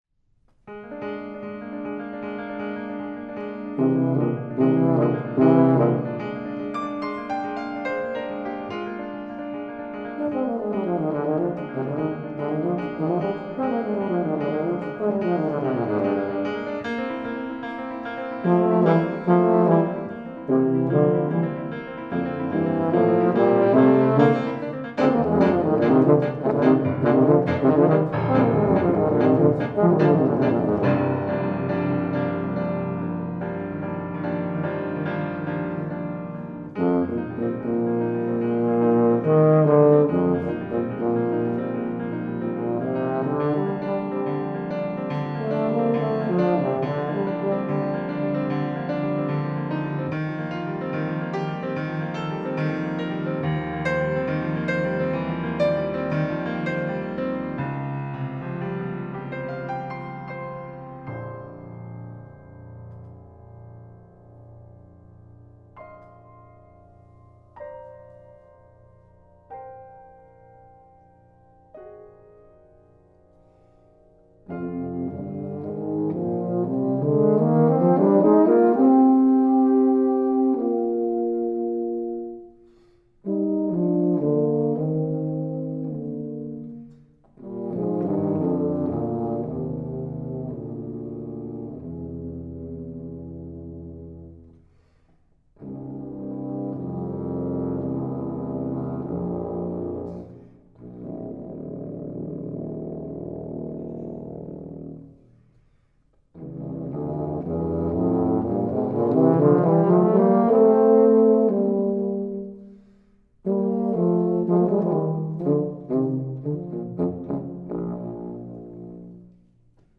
Faculty Recital 4-5-2012
tuba
piano